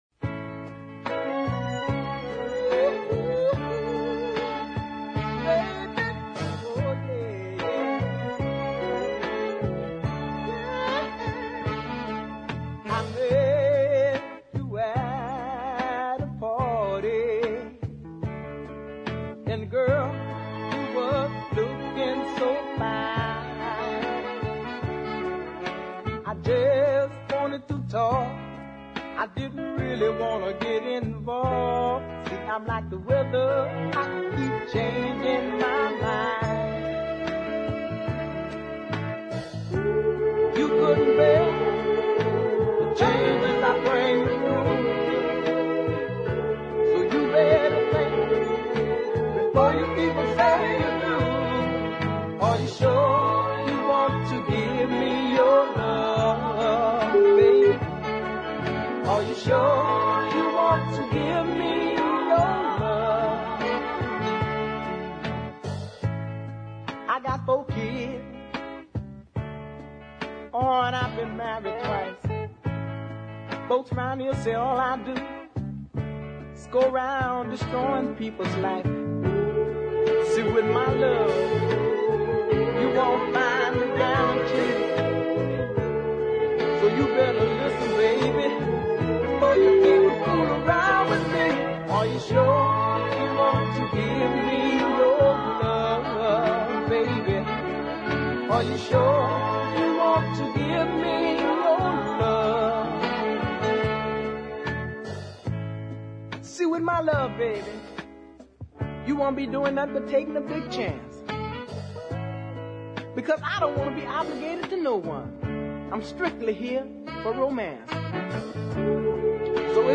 wonderfully tuneful Crescent City ballad